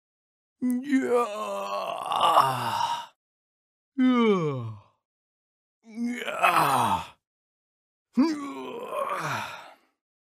Звуки кряхтения
Ленивое кряхтение в нескольких вариантах
Разные способы лениво покряхтеть